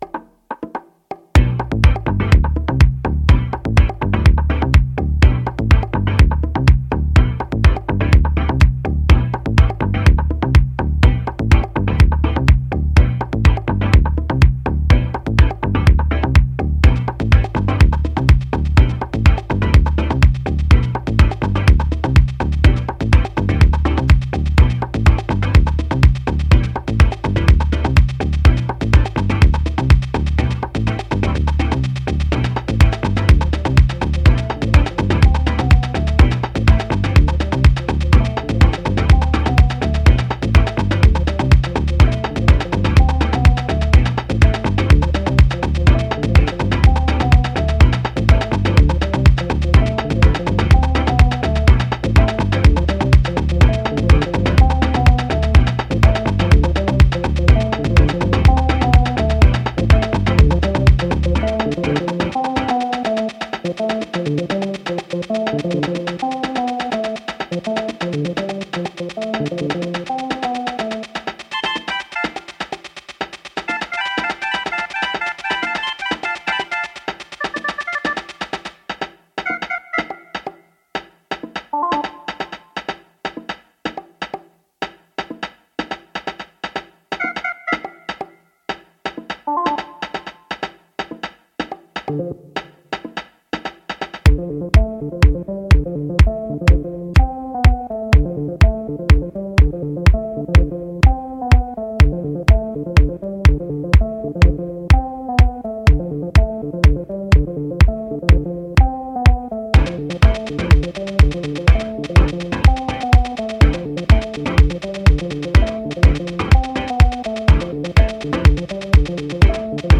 Neon wonders, acid lines and crunching ghetto bass
House Acid